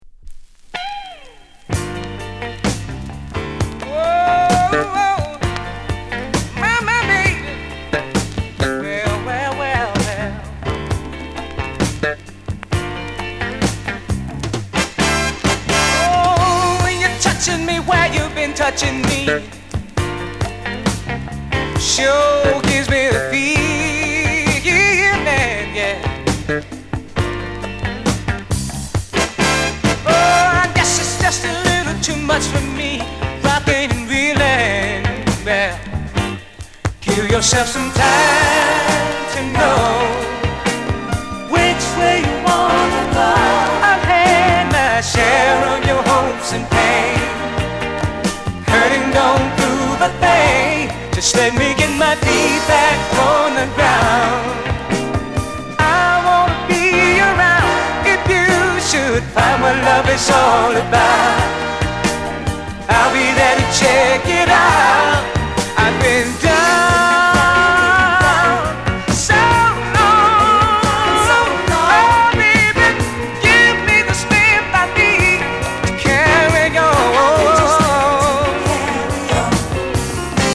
Genre: Rare Groove